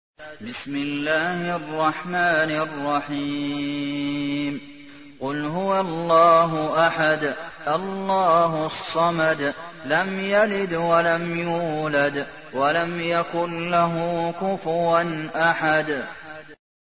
تحميل : 112. سورة الإخلاص / القارئ عبد المحسن قاسم / القرآن الكريم / موقع يا حسين